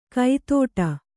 ♪ kaitōṭa